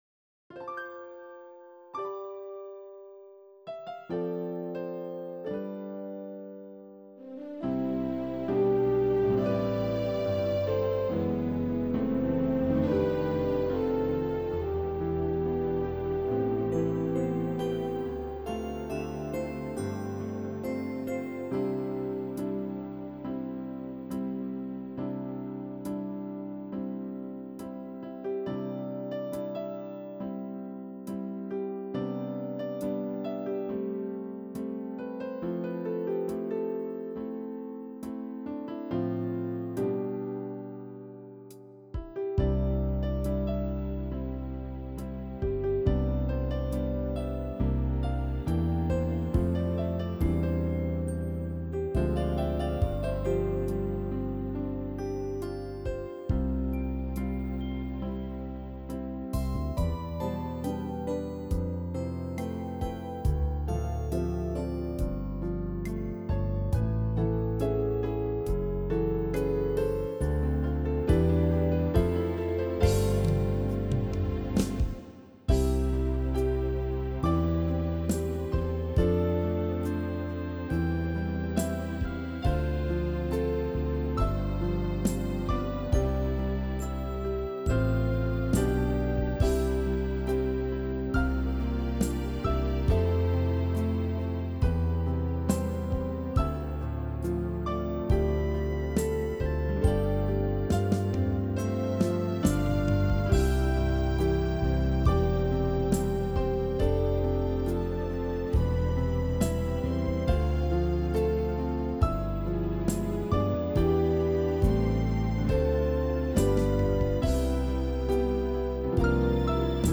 Enjoy your crispy 24-bit audio!